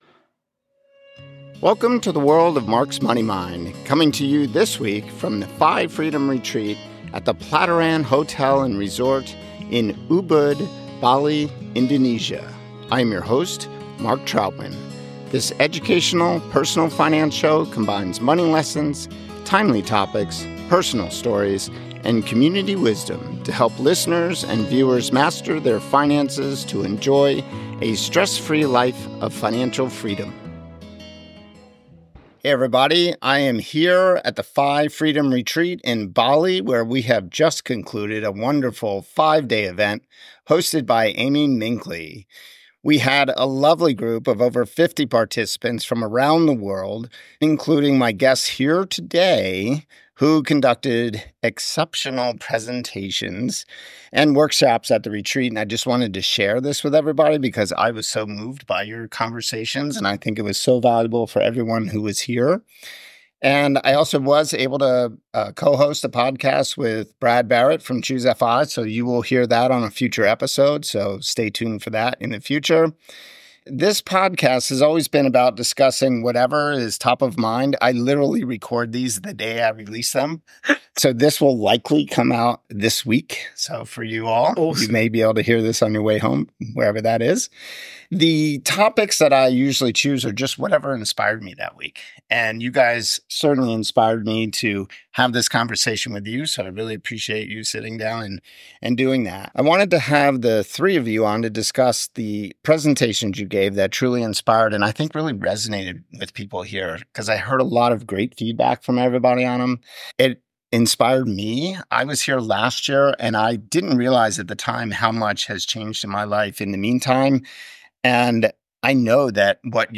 This episode takes place at the FI Freedom Retreat in Bali, Indonesia.